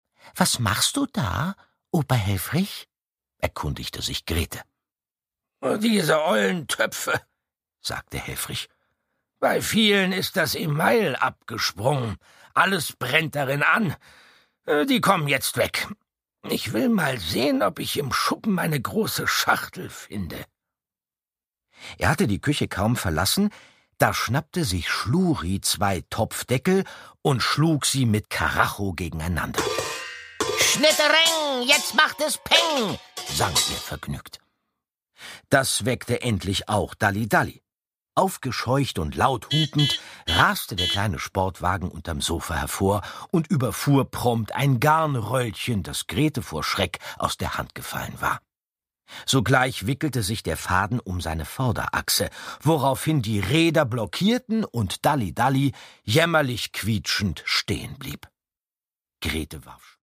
Produkttyp: Hörbuch-Download
Gelesen von: Andreas Fröhlich